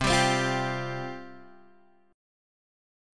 Csus4 chord